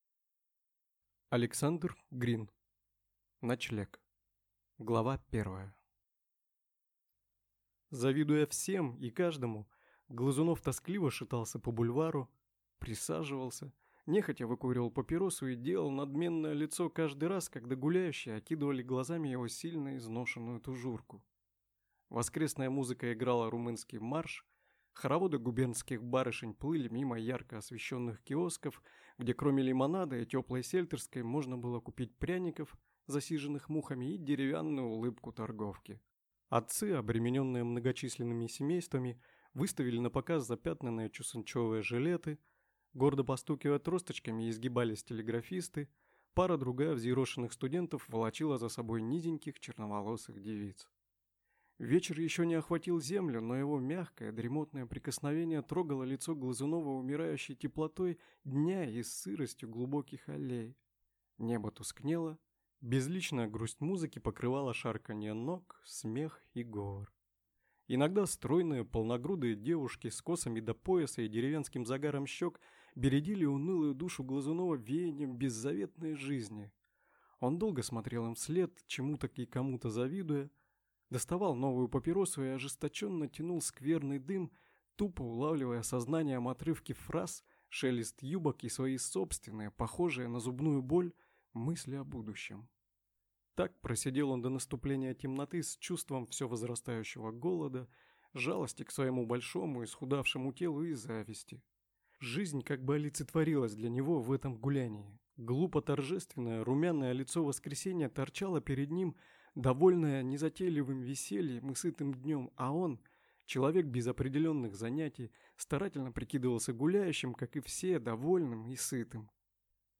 Аудиокнига Ночлег | Библиотека аудиокниг